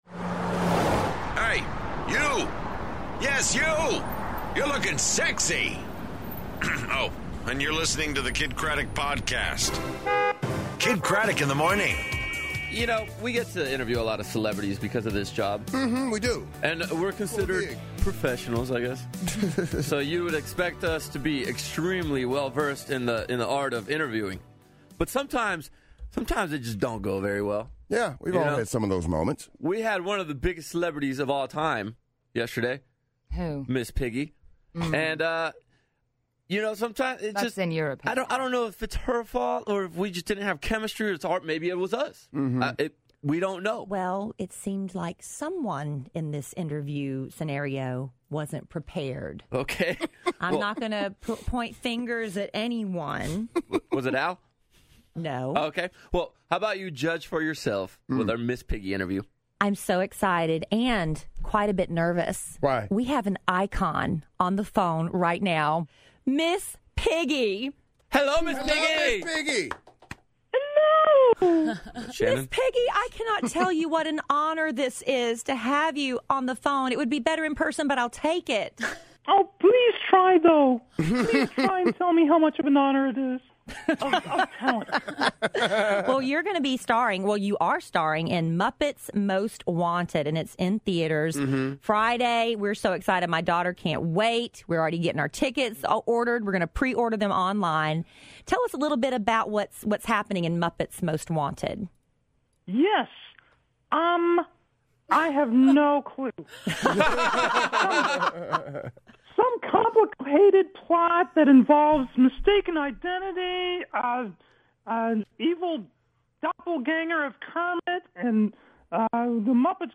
Our Bad Interviews, A Great Big World in Studio, And Open Lines